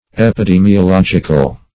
\ep`i*de`mi*o*log"ic*al\, a.
epidemiological.mp3